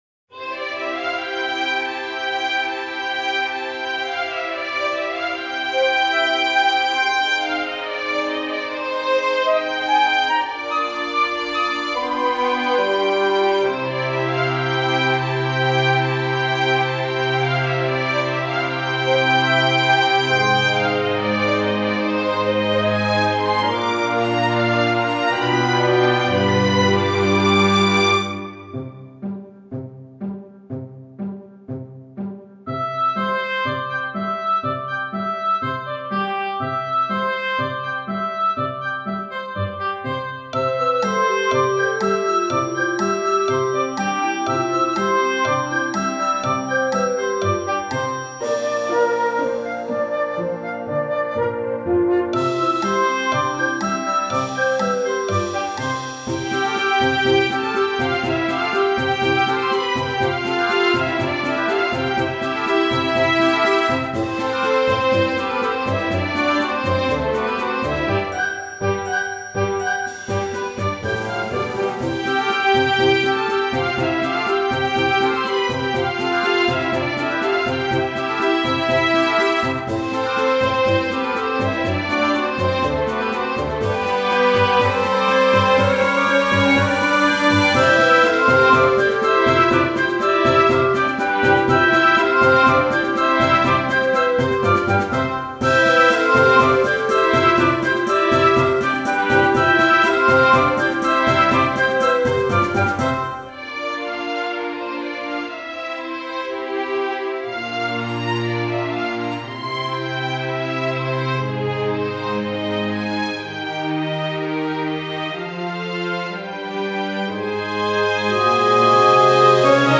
-oggをループ化-   朝 ほがらか 2:48 mp3